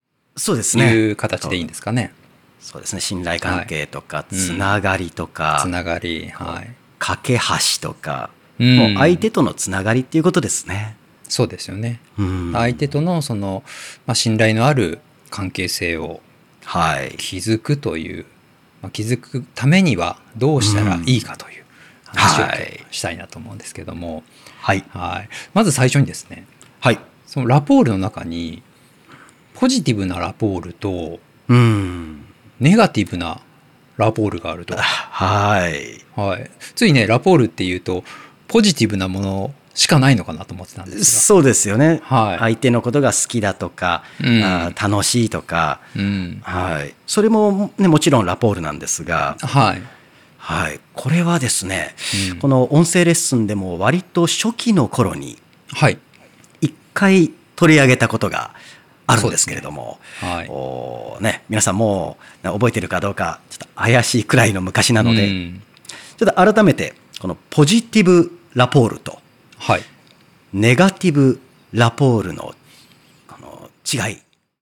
この音声講座は上記の「カートに入れる」ボタンから個別購入することで聴くことができます。